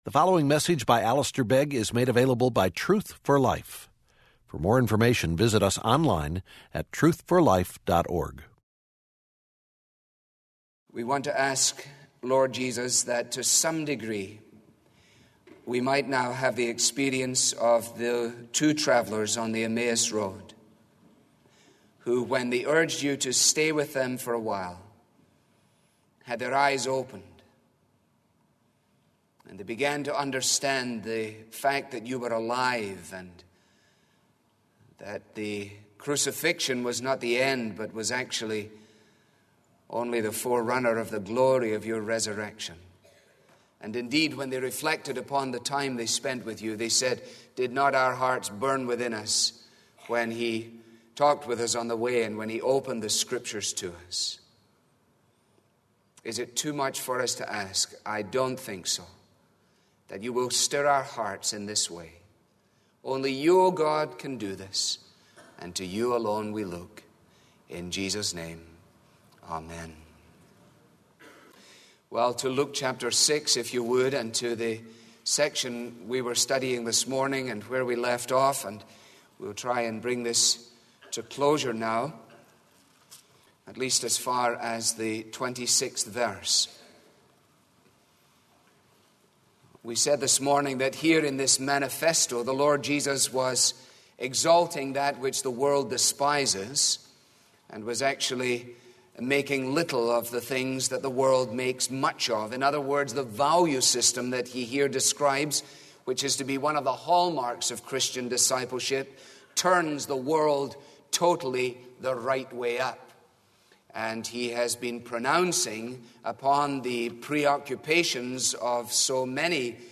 Sermons on dependence-on-god with audio from Truth For Life